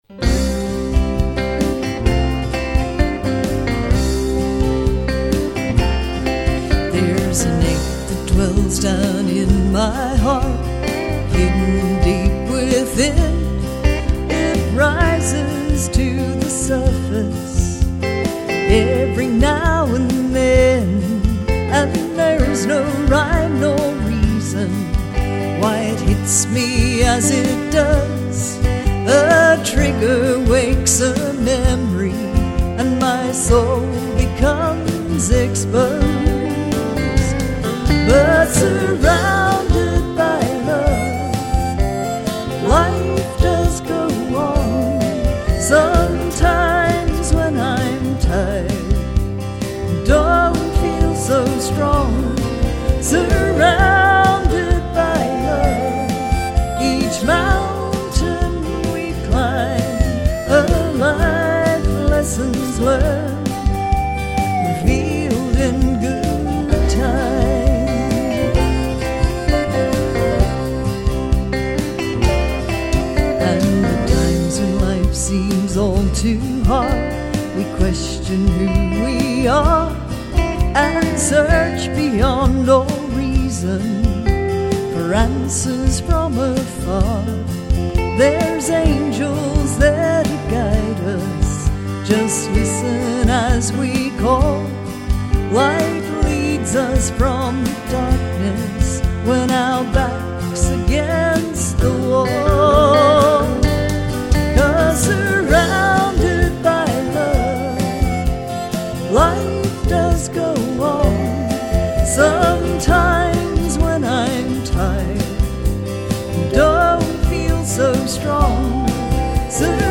an uplifting song of hope
using a selection of Australia’s finest musicians.